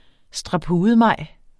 Udtale [ sdʁɑˈpuːðəˌmɑjˀ ]